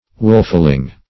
wolfling - definition of wolfling - synonyms, pronunciation, spelling from Free Dictionary Search Result for " wolfling" : The Collaborative International Dictionary of English v.0.48: Wolfling \Wolf"ling\, n. A young wolf.